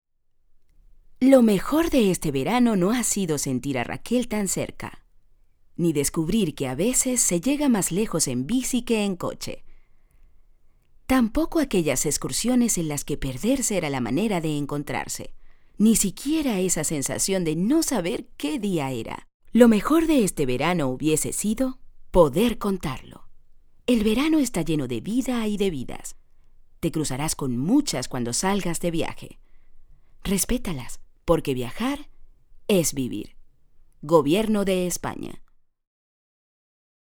spanisch Südamerika
Sprechprobe: Sonstiges (Muttersprache):